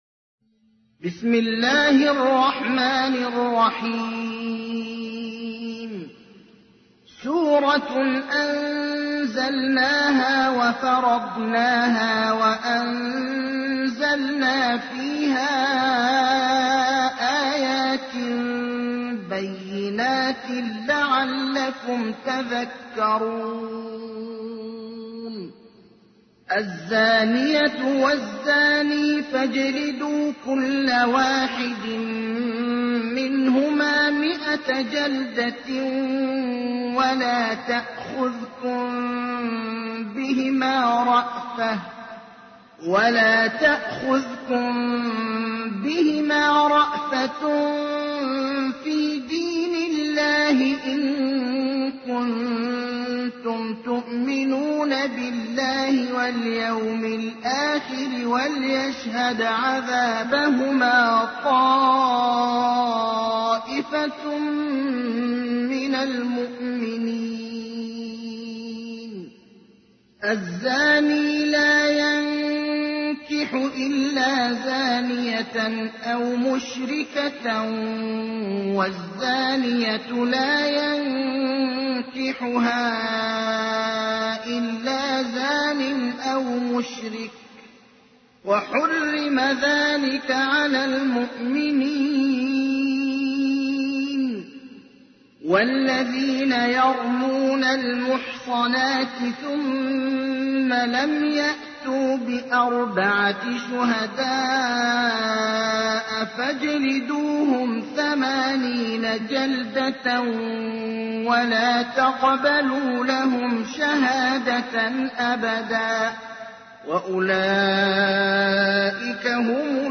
سورة النور / القارئ ابراهيم الأخضر / القرآن الكريم / موقع يا حسين